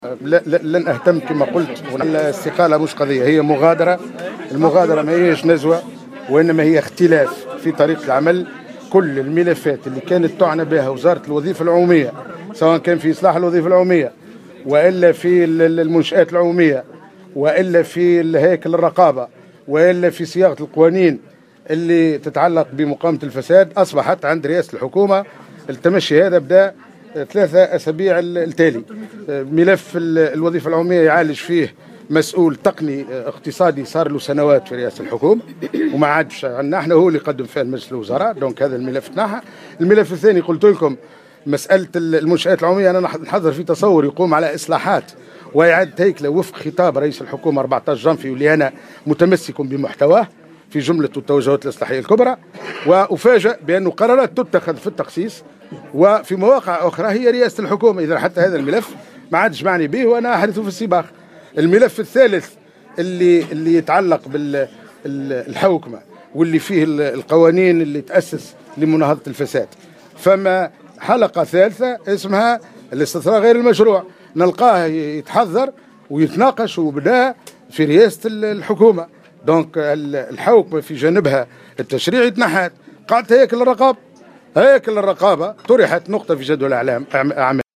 أكد وزير الوظيفة العمومية المنتهية مهامه عبيد البريكي في تصريح لمراسل الجوهرة "اف ام" على هامش ندوة صحفية عقدها اليوم أنه لايهتم بتسمية بين استقالة أو اقالة مؤكدا أنها مغادرة وهي ليست نزوة بل جاءت على خلفية اختلافات عميقة بينه وبين الحكومة في طريقة العمل على كل الملفات على حد قوله.